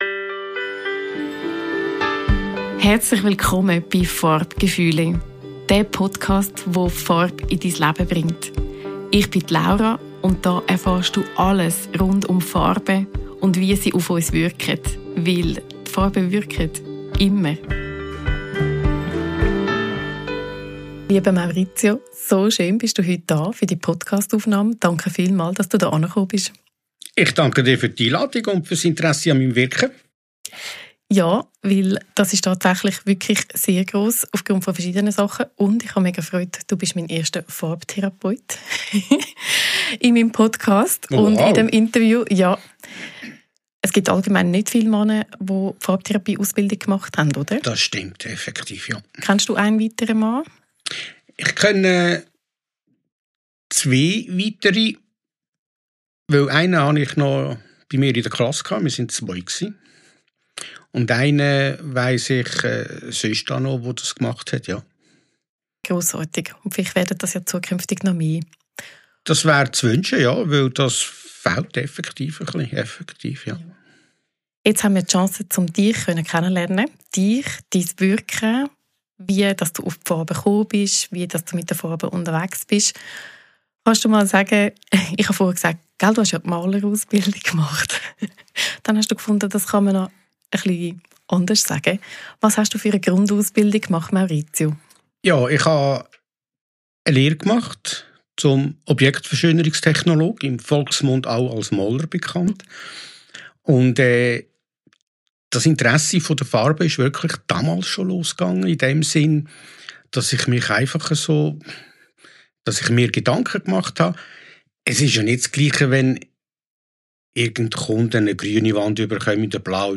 #13 Interview